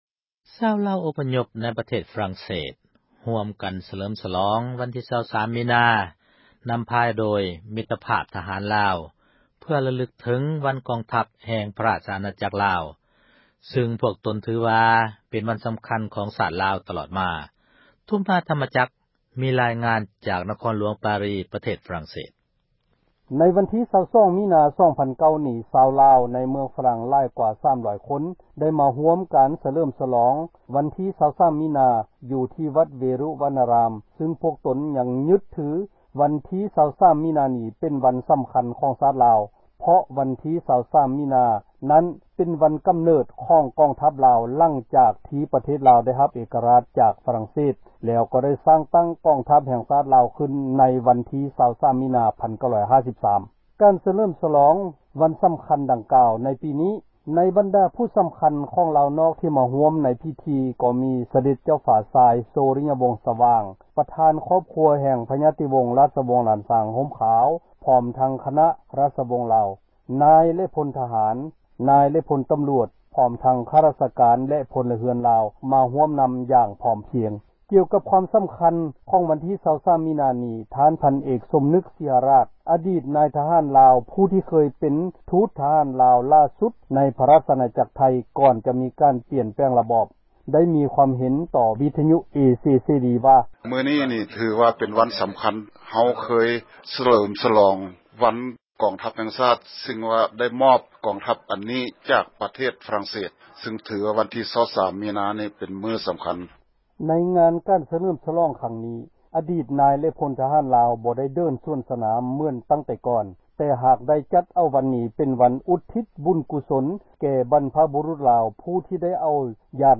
ມີຣາຍງານຈາກ ກຸງປາຣີປະເທດຝຣັ່ງເສດ.